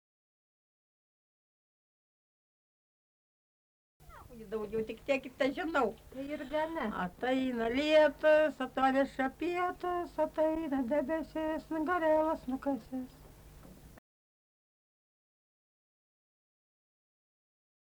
smulkieji žanrai
Obeliai
vokalinis